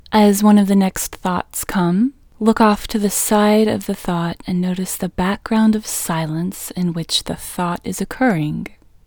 WHOLENESS English Female 3